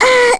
Lilia-Vox_Damage_01.wav